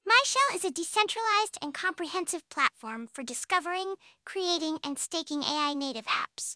Even with the reference voices provided (in the repository), both v1 and v2 are less accurate than the website when run on my machine.